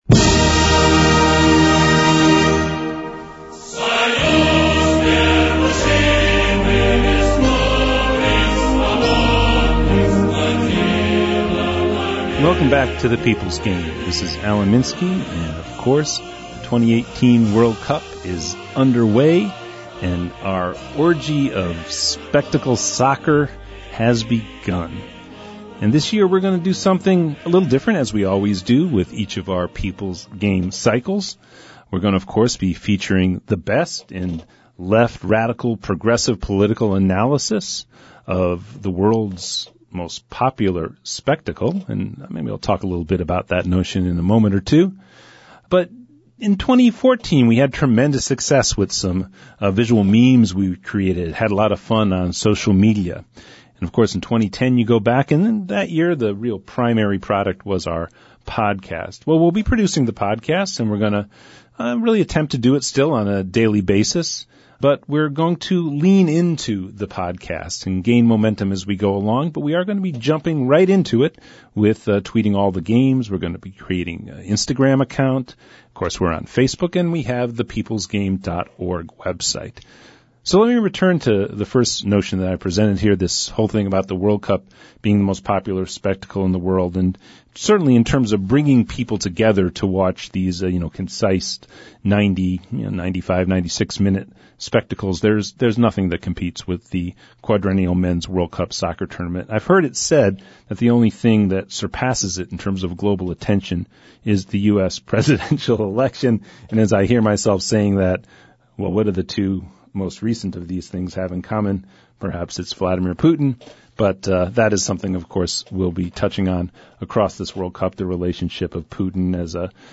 reports from Moscow on the never-ending fiesta with the traveling fans of El Tri.